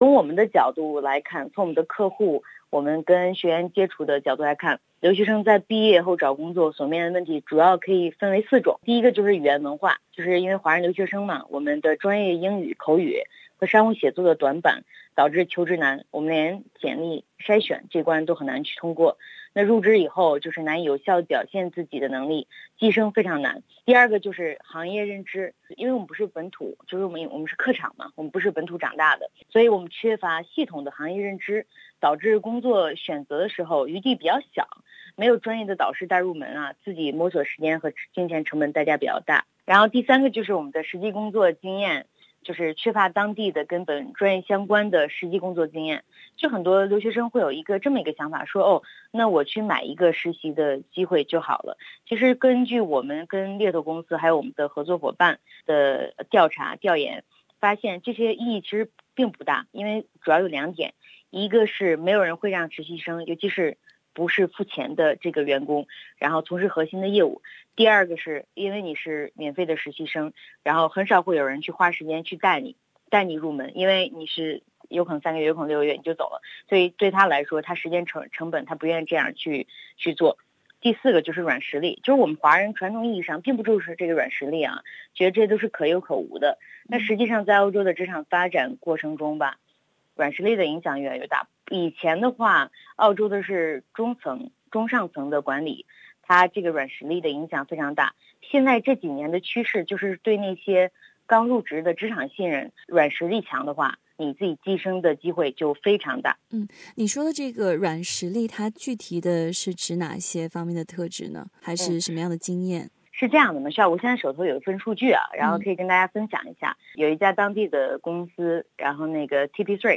career consultant